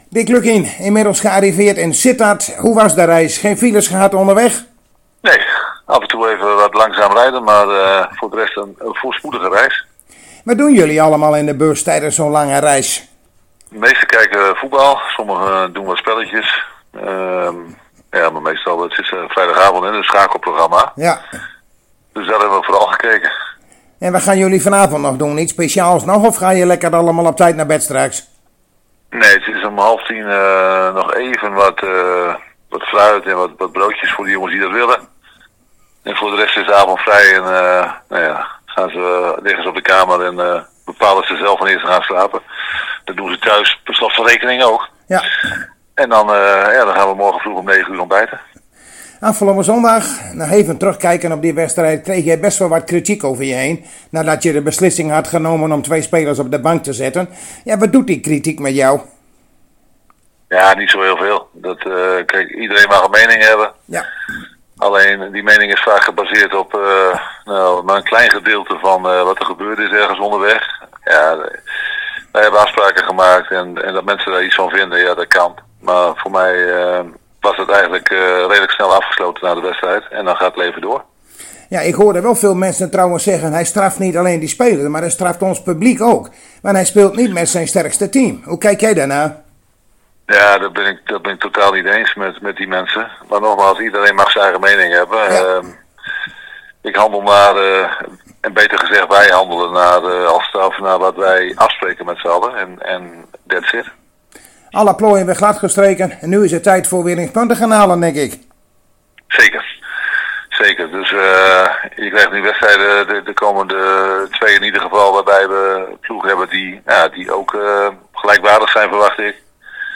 Zojuist spraken wij weer met de net in de buurt van Sittard gearriveerde trainer Dick Lukkien en dat is hier te beluisteren.